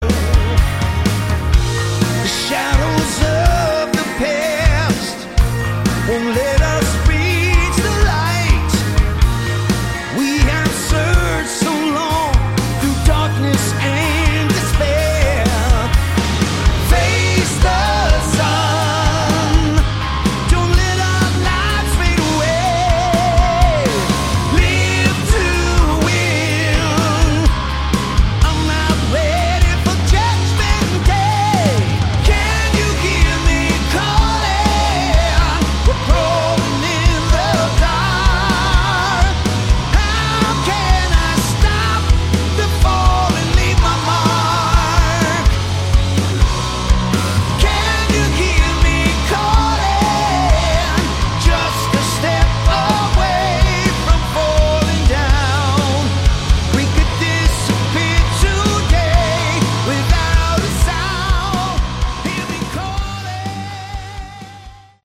Category: AOR / Melodic Hard Rock
vocals
guitar
keyboards
bass
drums